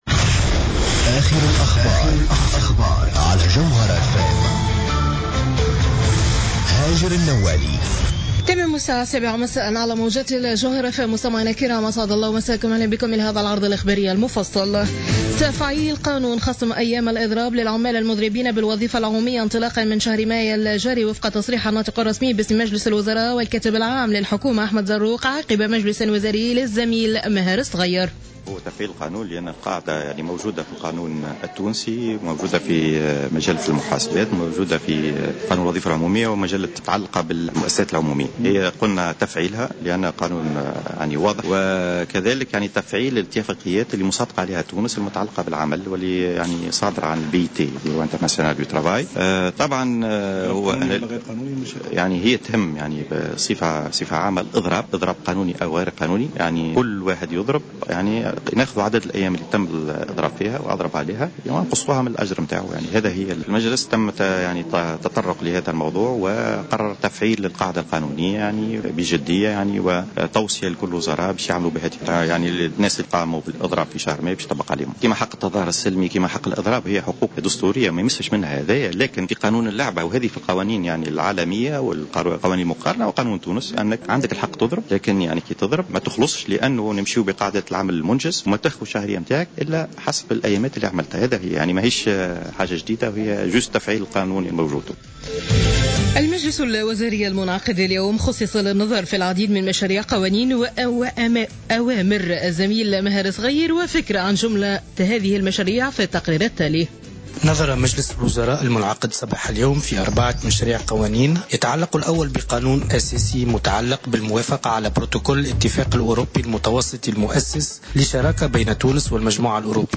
نشرة أخبار السابعة مساء ليوم الإربعاء 20 ماي 2015